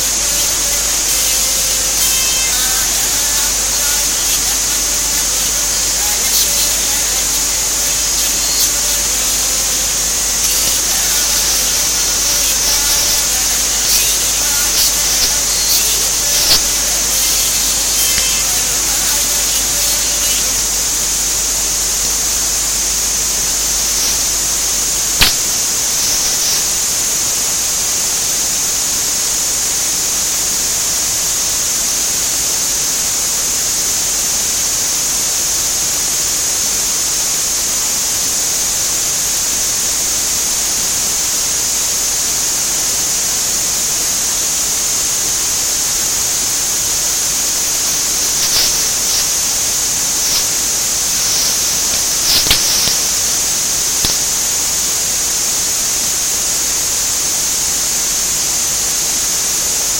So here I connected a CD player to an amplifier.
The music was switched off a third of the way down the recording and the EM field and the music disappeared.
music up to 20 secs and switch off